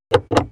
Encender la luz interior de un coche RAV4